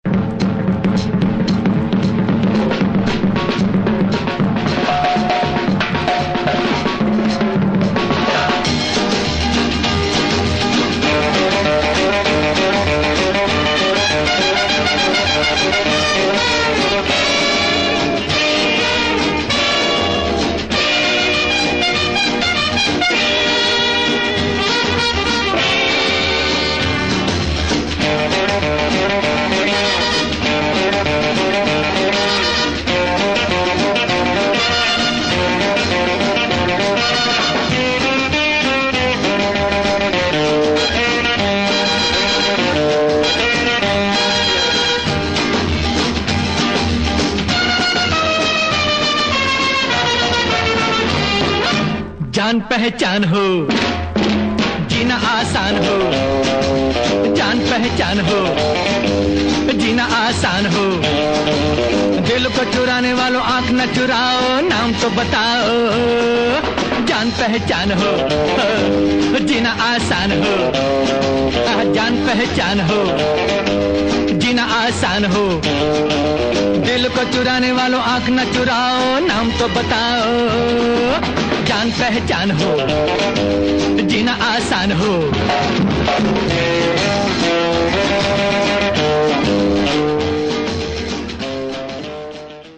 Indian twist !